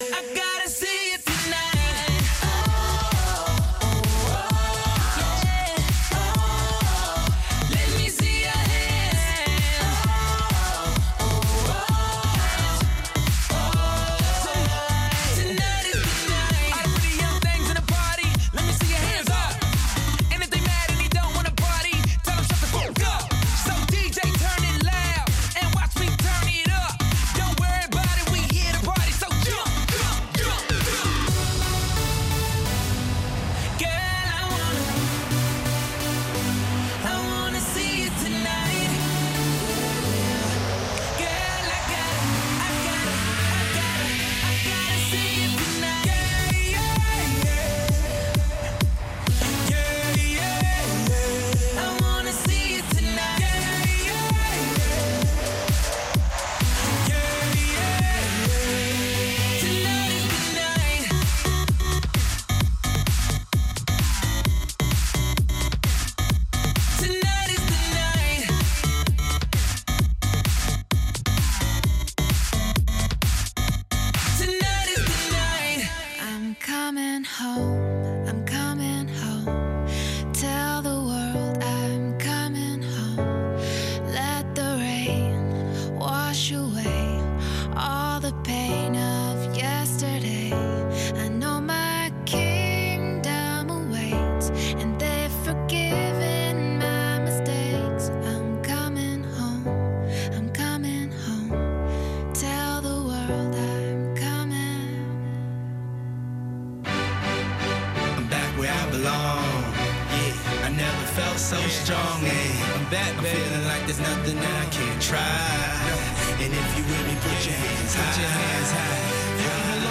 Nightline Africa is a 60-minute news magazine program highlighting the latest issues and developments on the continent. Correspondents from Washington and across Africa offer in-depth interviews, analysis and features on African arts and culture, sports, and music